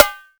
SNARE.22.NEPT.wav